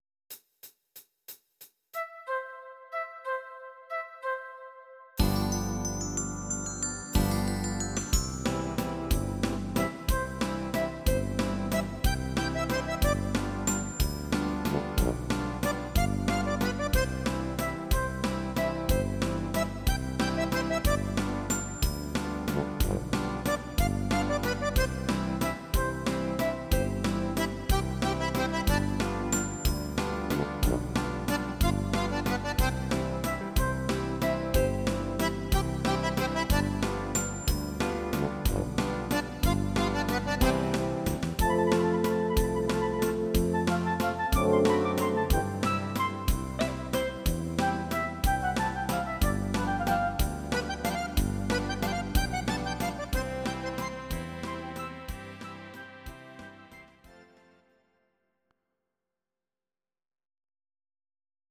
These are MP3 versions of our MIDI file catalogue.
Please note: no vocals and no karaoke included.
Akkordeon